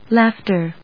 音節laugh・ter 発音記号・読み方
/lˈæftɚ(米国英語), lάːftə(英国英語)/